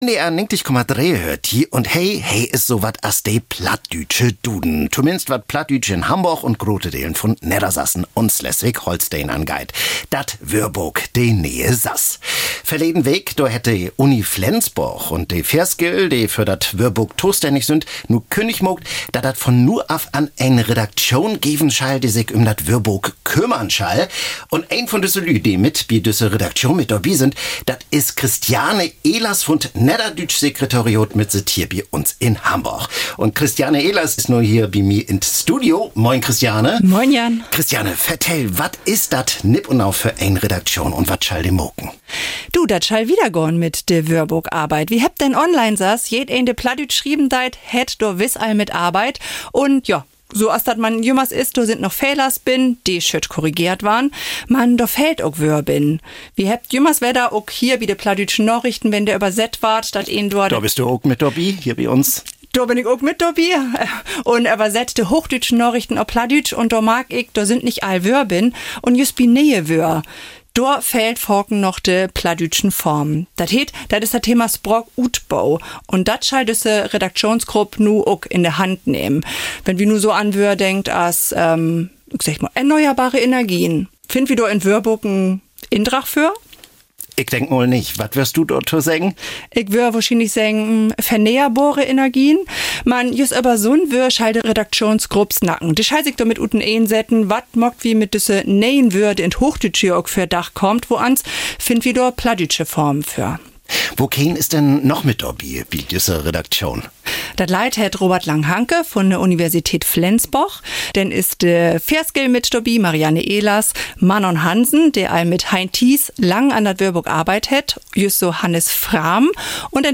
Dorto geev dat annerletzt bi NDR 90,3 düssen Bericht.